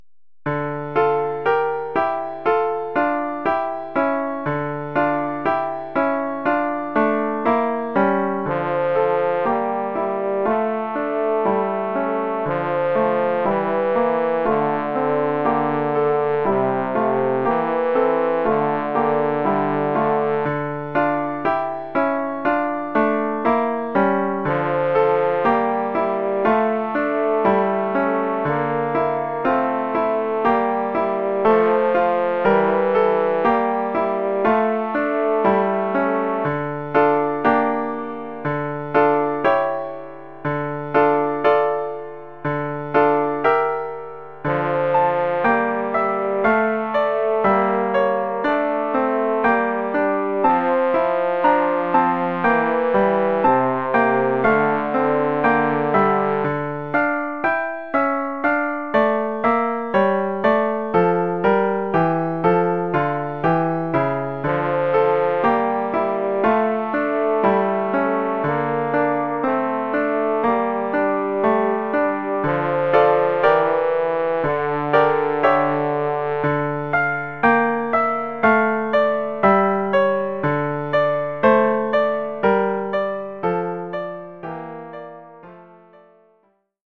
Oeuvre pour saxhorn basse et piano.